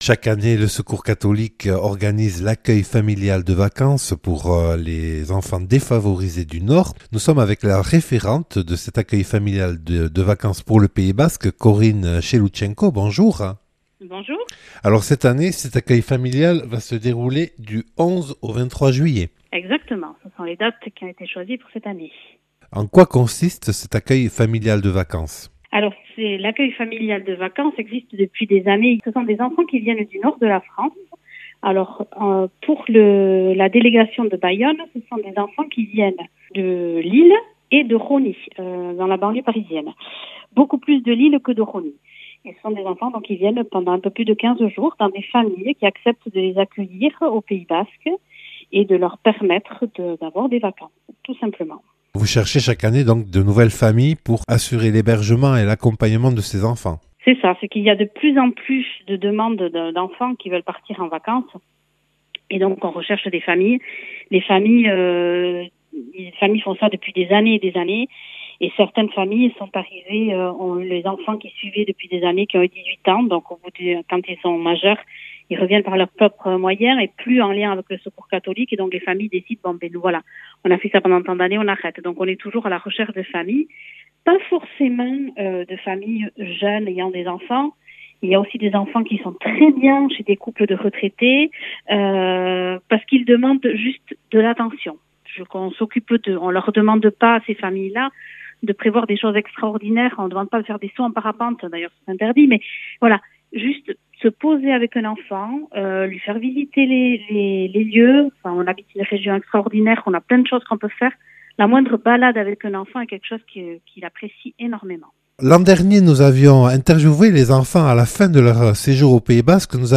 Accueil \ Emissions \ Infos \ Interviews et reportages \ L’accueil familial de vacances des enfants défavorisés du 11 au 23 juillet (...)